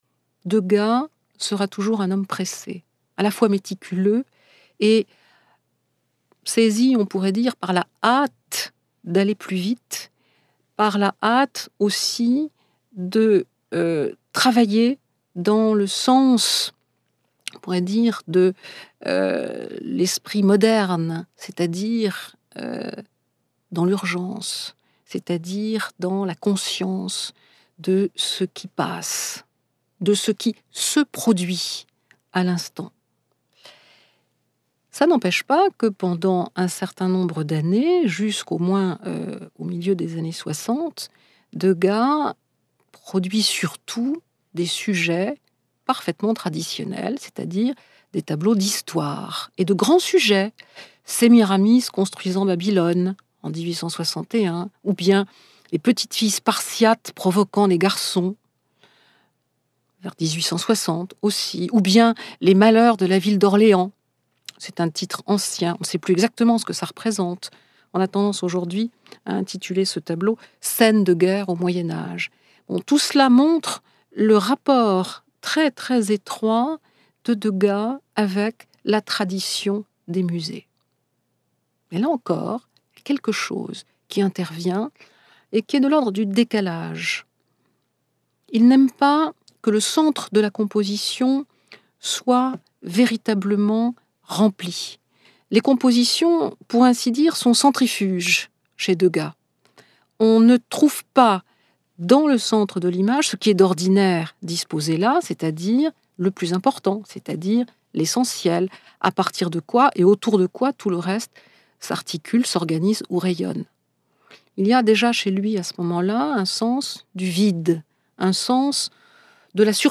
enregistré sur le vif de la parole